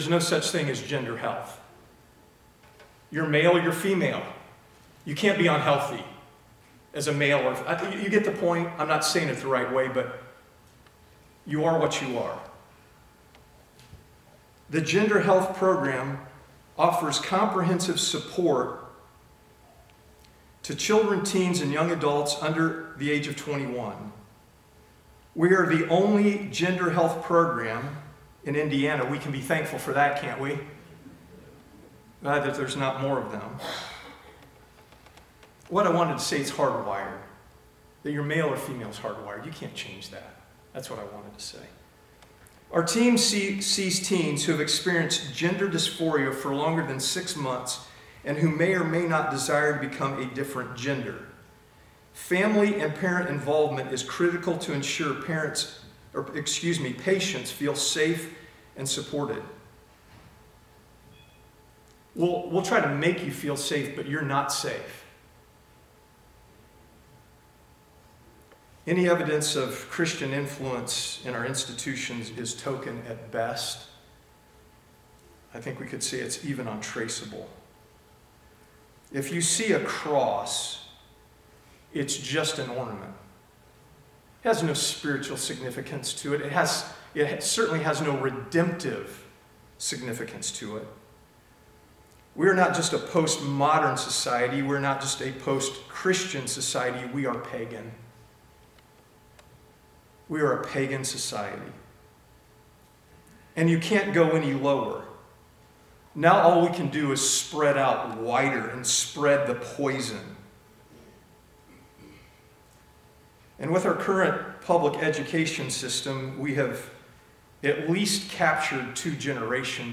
This is the first installment in a 3-part sermon series.